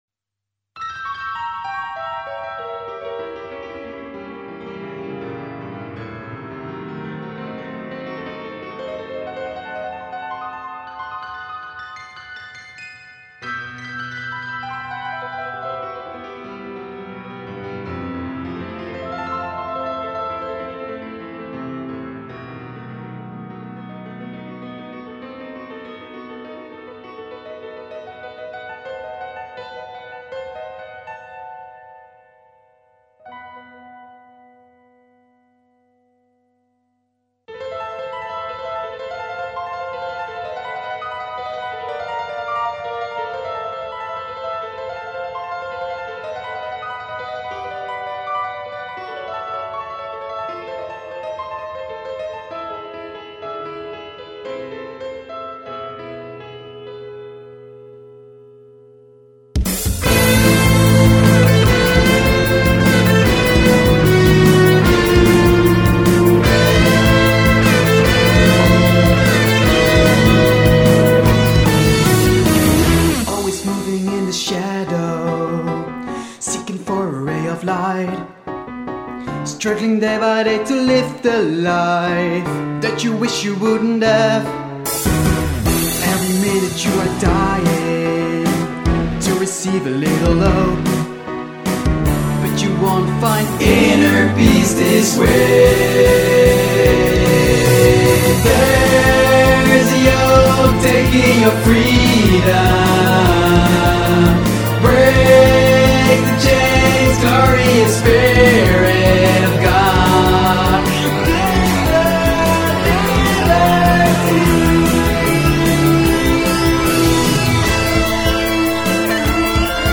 toetsen en gitaren
zang en koortjes